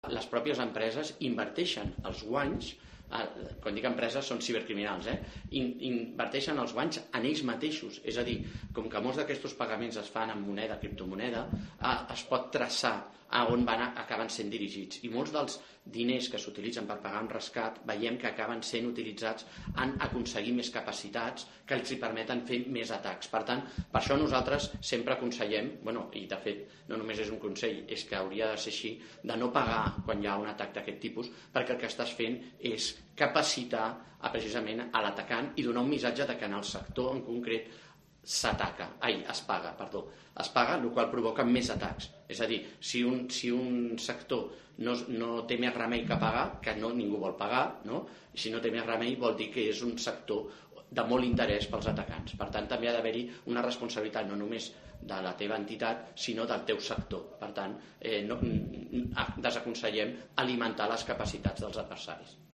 Tomàs Roy, director de la agencia de ciberseguridad de Cataluña, sobre los ciberatacantes